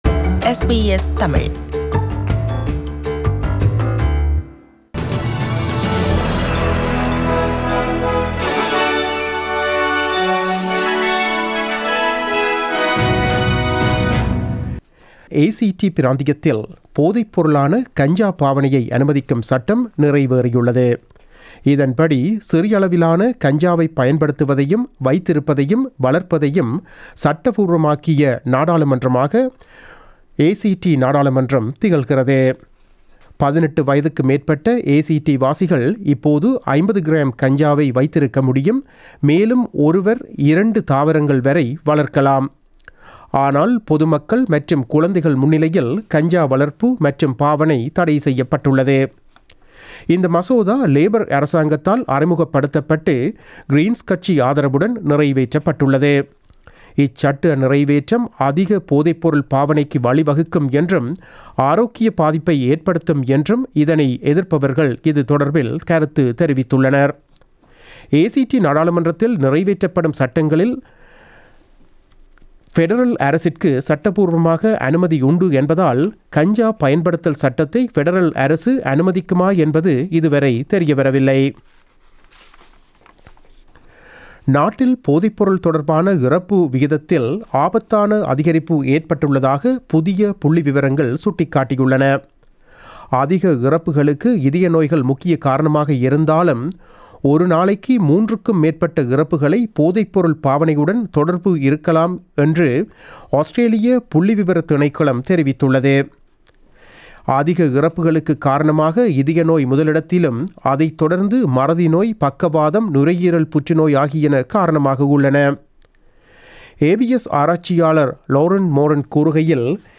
நமது SBS தமிழ் ஒலிபரப்பில் இன்று புதன்கிழமை (25 September 2019) இரவு 8 மணிக்கு ஒலித்த ஆஸ்திரேலியா குறித்த செய்திகள்.
Australian News on the latest broadcast of SBS Tamil Source: SBS Tamil